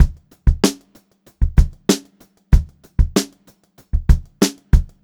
Index of /90_sSampleCDs/USB Soundscan vol.38 - Funk-Groove Drumloops [AKAI] 1CD/Partition B/05-95 STRGHT
95STRBEAT4-L.wav